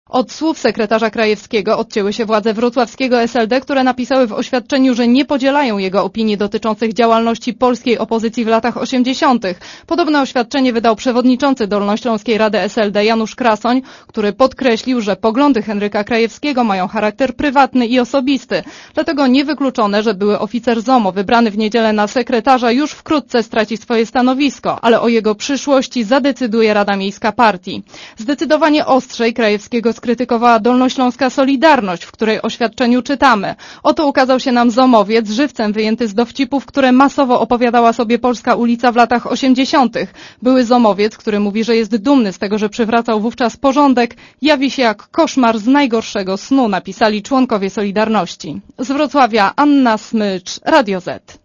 reporterki Radia ZET*